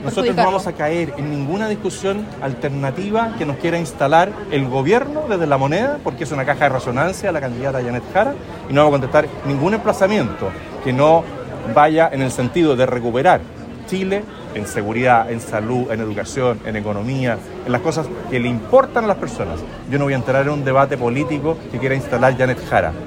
Estas declaraciones las emitió en su llegada al Aeropuerto Carriel Sur de Talcahuano, en el marco de la segunda vuelta presidencial, donde se enfrentará a la candidata del oficialismo Jeannette Jara.